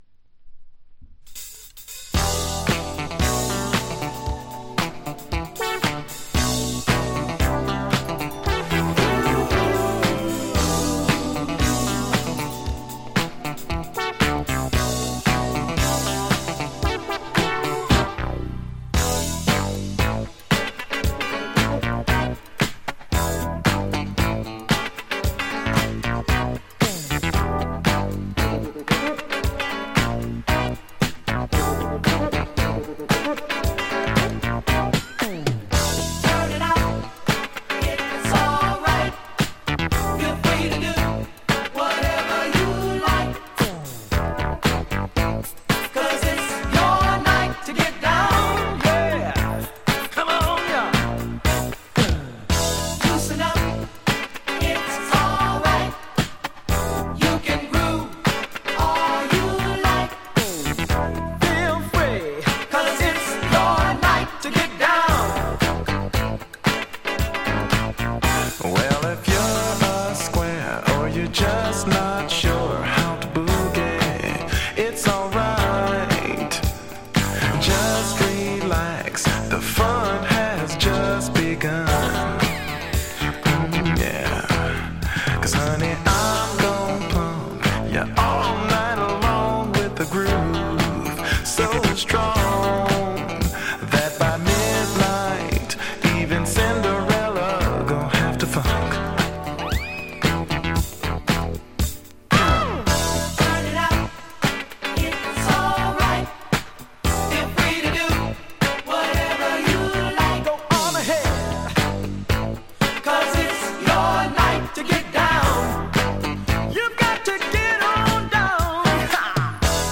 Disco Funk at its bestな超ご機嫌な仕上がりの名盤！
過去に販売した盤（日本盤のEX）からの録音です。